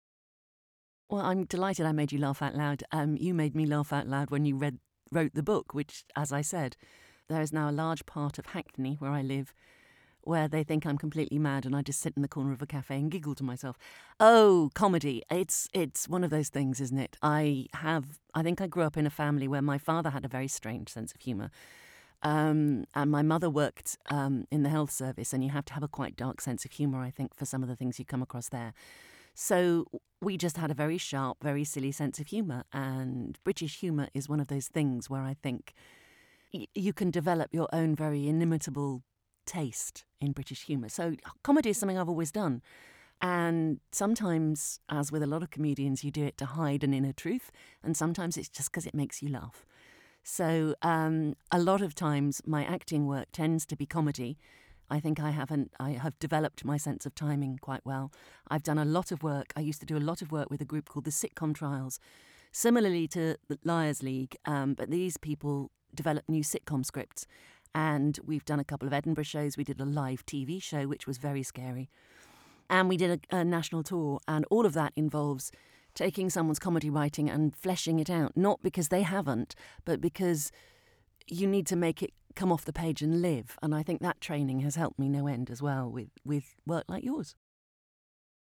I hope you dug listening to the interview as much as I did and will take a listen to the audiobook.